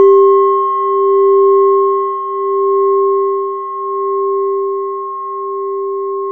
Index of /90_sSampleCDs/E-MU Formula 4000 Series Vol. 4 – Earth Tones/Default Folder/Japanese Bowls